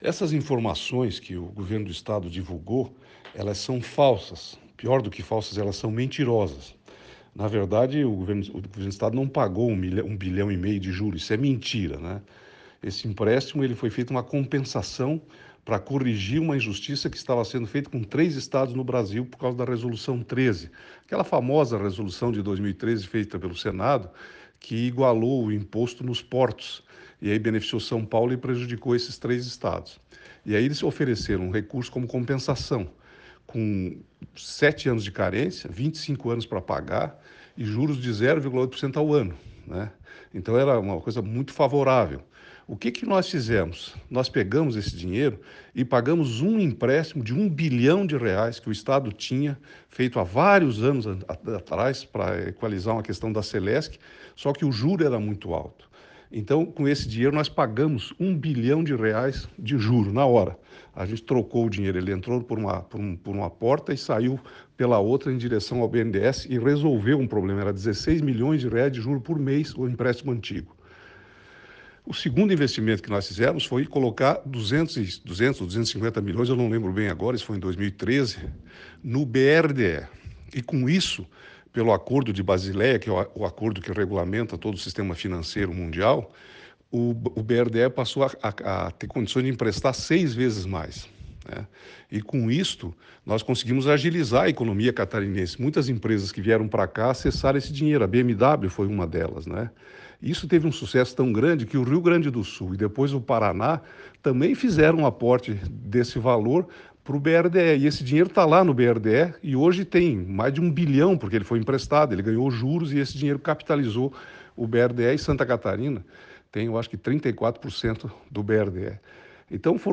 “Quem não tem o que falar de si próprio, fala mal dos outros. Esta é a realidade que estamos enfrentando com o governo do Estado. A mentira tem perna curta e ela não dobra a esquina, já se esclarece no mesmo lugar onde ela é apontada”, disse Colombo; ouça a declaração